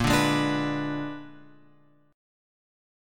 A# 7th Flat 9th